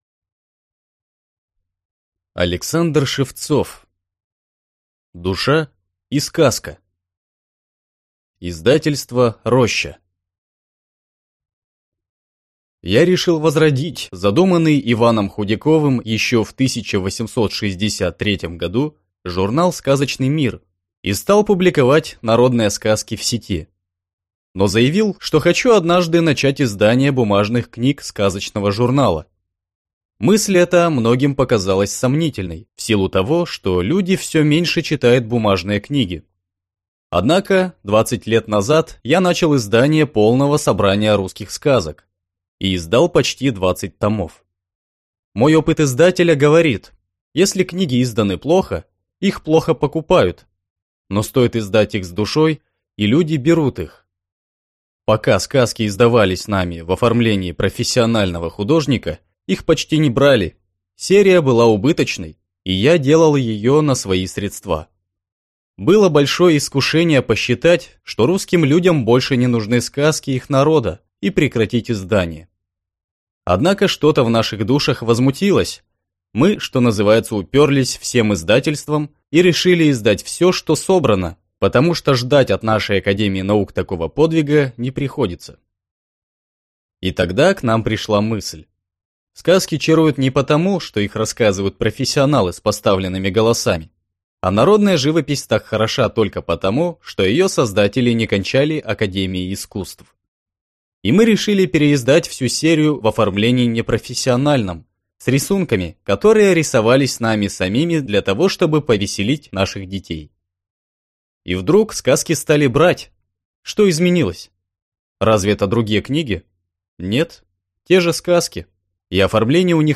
Аудиокнига Душа и сказка | Библиотека аудиокниг